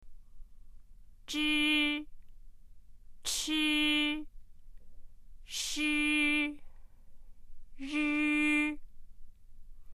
（　）の母音をつけた第一声の発音を聞いてみましょう。
zh　（i）　　 ch　（i）　 sh　（i）　　 r　（i）
zhi-chi-shi-ri.mp3